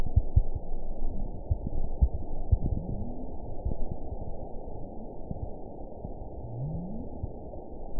event 915826 date 12/15/22 time 22:27:24 GMT (2 years, 4 months ago) score 9.09 location TSS-AB05 detected by nrw target species NRW annotations +NRW Spectrogram: Frequency (kHz) vs. Time (s) audio not available .wav